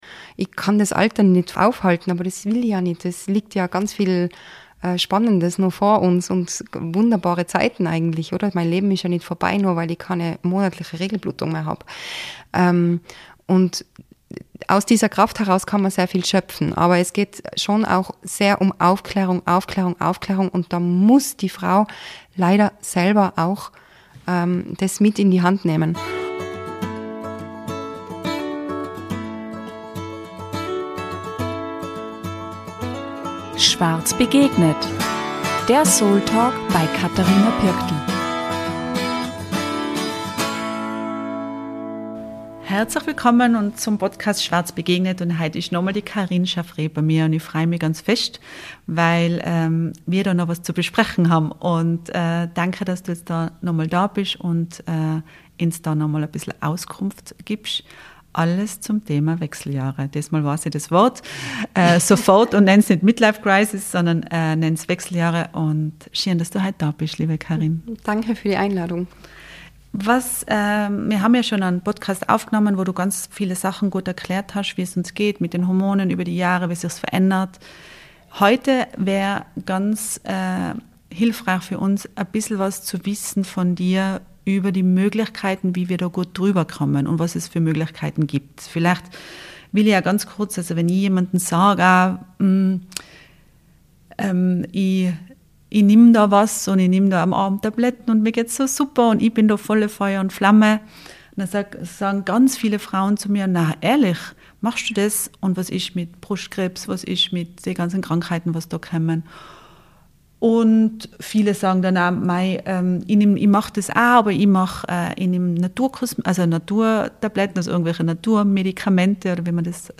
Sie zeigt, warum Aufklärung so wichtig ist – und warum jede Frau das Recht hat, sich gut versorgt zu fühlen. Ein Gespräch über Eigenverantwortung, körperliches und seelisches Wohlbefinden – und darüber, wie viel Kraft in einem neuen Lebensabschnitt steckt.